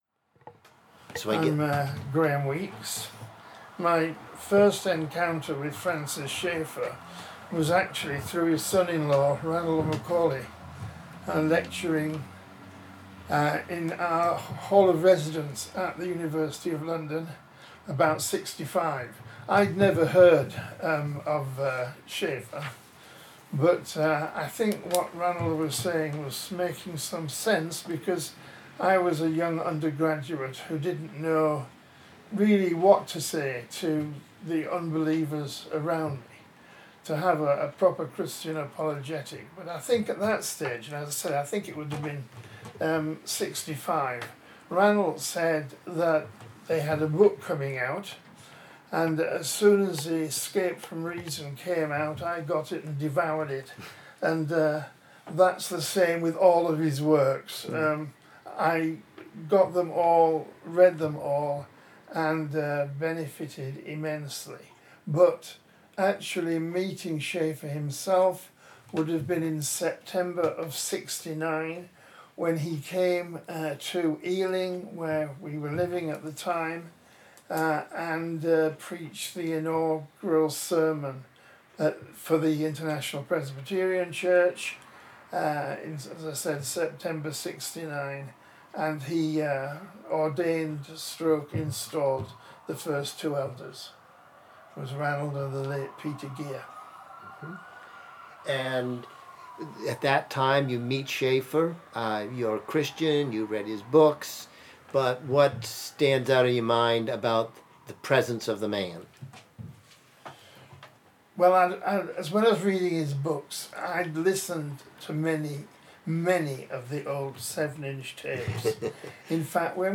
Francis A. Schaeffer Oral History Interviews collection